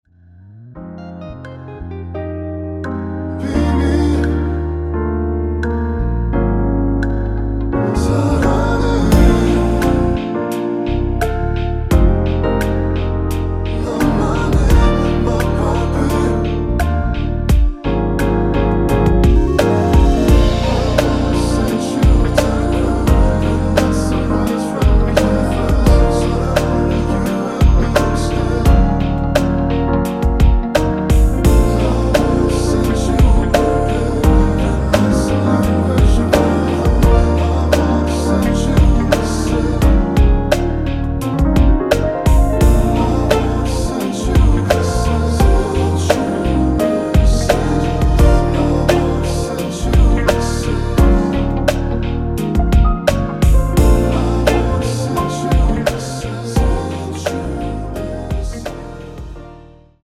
원키에서(-2)내린 코러스 포함된 MR입니다.(미리듣기 확인)
Db
앞부분30초, 뒷부분30초씩 편집해서 올려 드리고 있습니다.
중간에 음이 끈어지고 다시 나오는 이유는